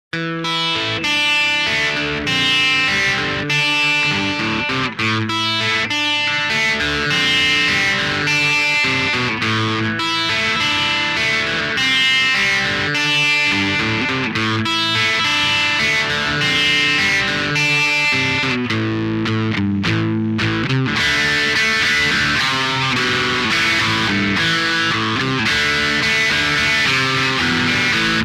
Telecaster '52 Reissue
Pups. Mástil y puente